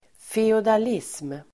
Ladda ner uttalet
feodalism substantiv (historiskt), feudalism [historical]Uttal: [feodal'is:m] Böjningar: feodalismenDefinition: ett samhällssystem med storgodsägare och livegna bönder